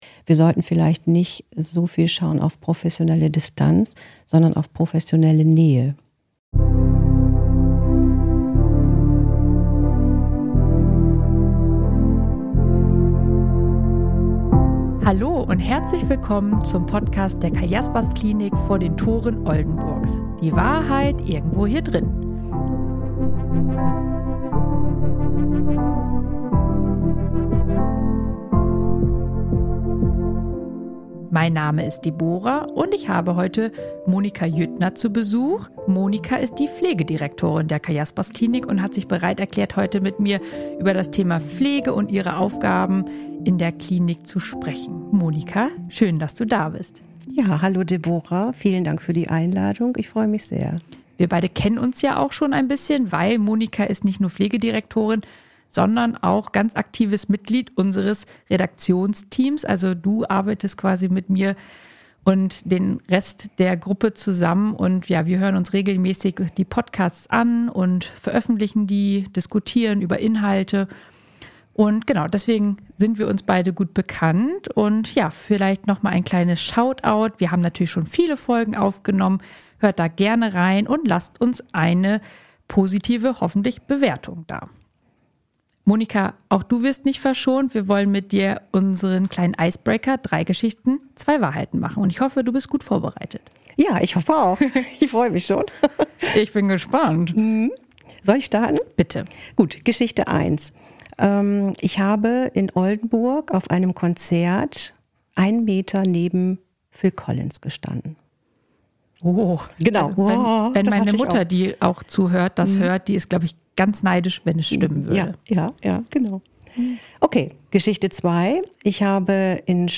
#40 PSYCHIATRISCHE PFLEGE Experten-Talk ~ Die Wahrheit Irgendwo Hier Drinnen Podcast